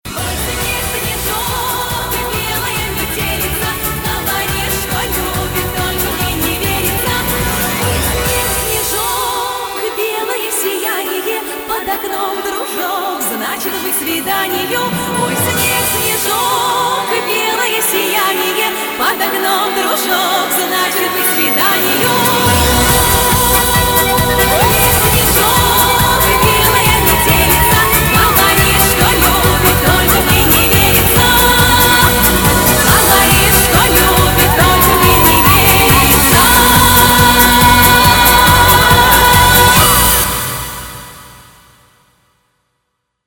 застольные
русские народные
Баян